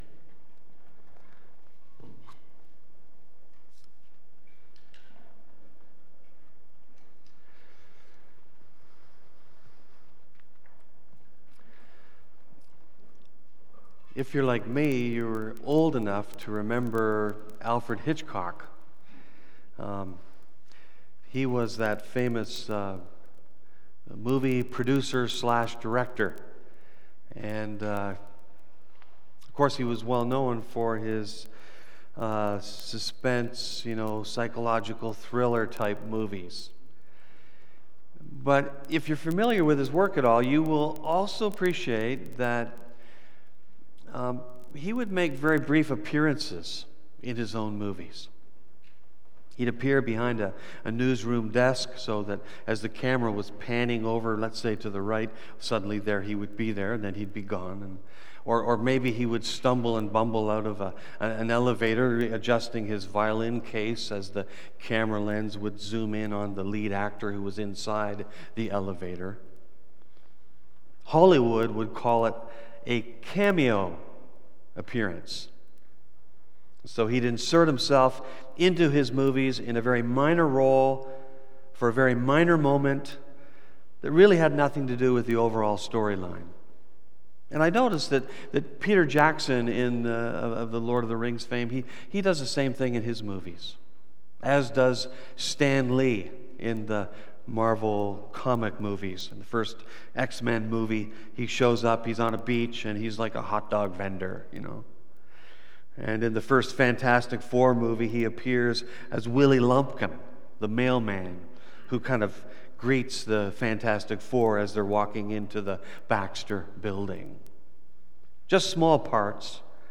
Part 52 BACK TO SERMON LIST Preacher